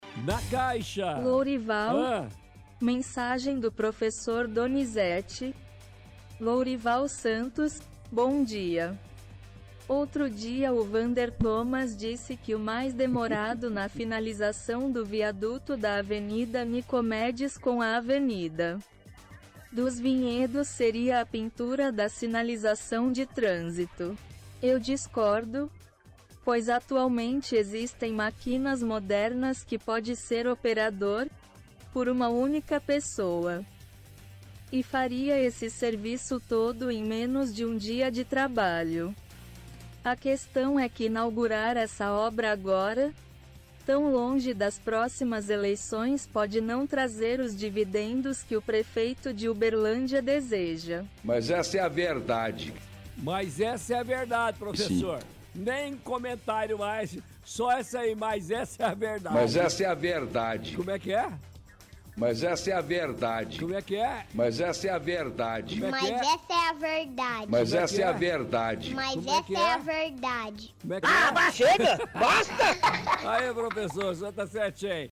– Áudio em voz do Google (ouvinte) critica demora para entrega do viaduto Nicomedes Alves dos Santos.